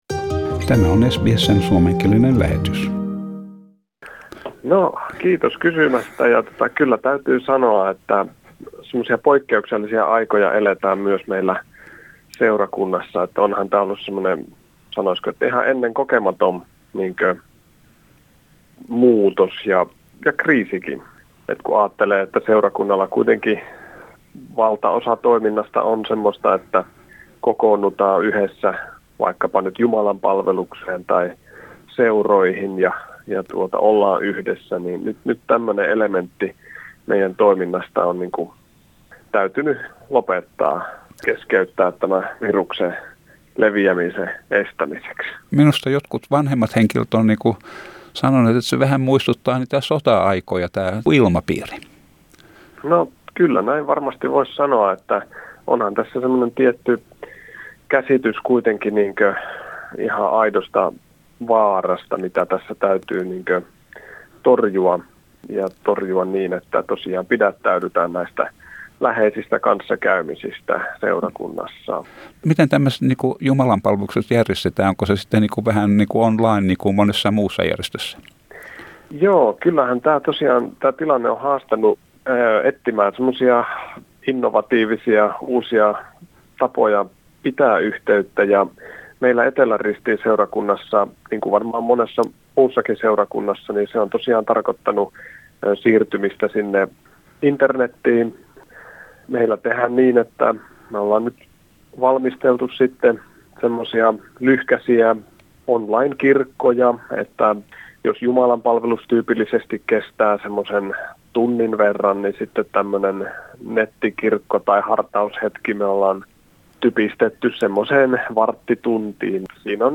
I started our conversation by asking, how the congregation is coping with the difficulties caused by the virus pandemic.